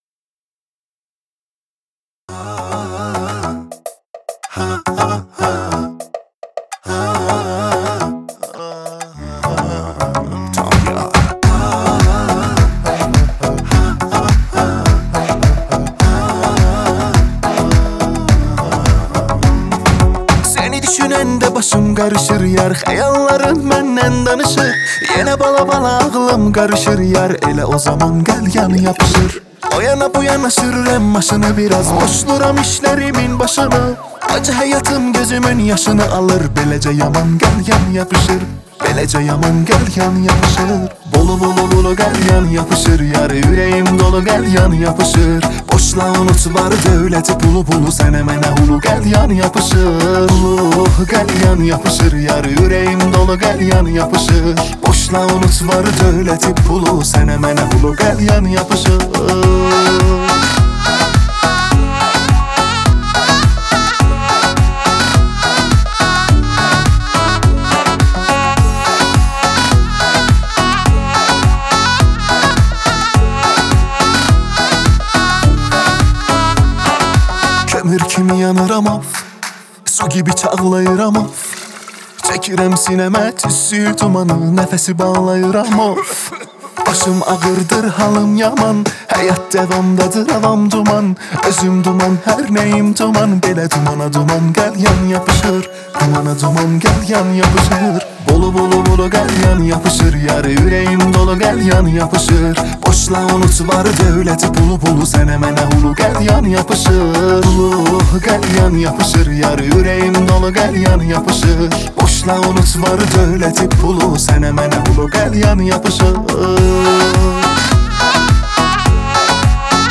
دسته بندی : دانلود آهنگ ترکی تاریخ : شنبه 24 آگوست 2019